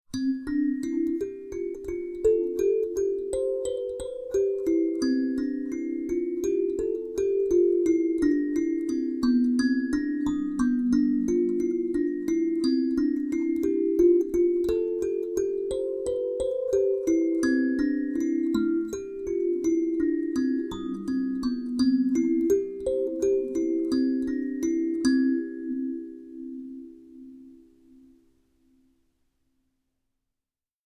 C Major!